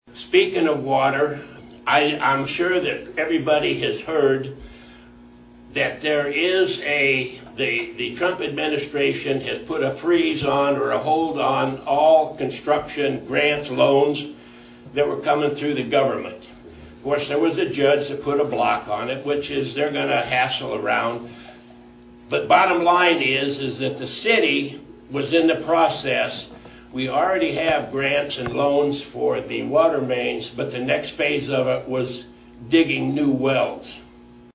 Mayor Phil Przychodizin, speaking at the recent Massena Annual Chamber of Commerce banquet, announced that the city is partnering with Greenfield Municipal Utilities to enhance the water treatment process.